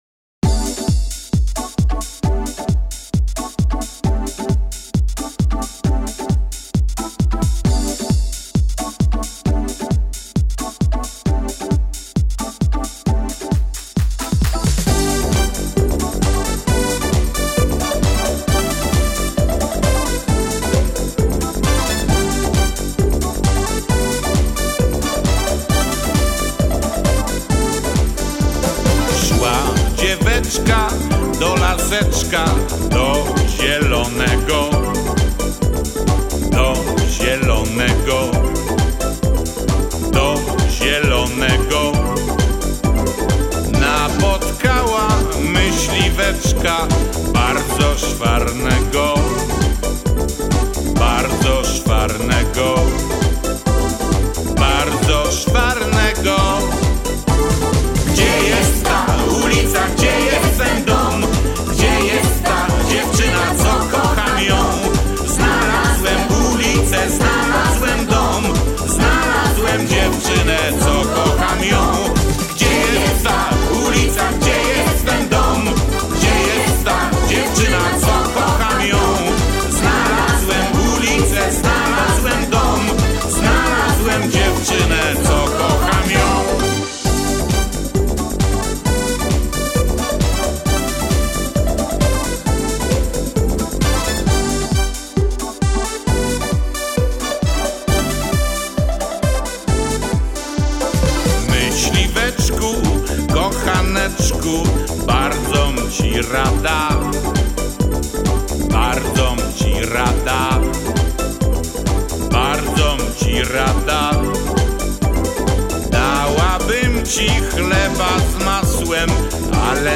Стиль ДИСКО - Андеграунд - ПА 50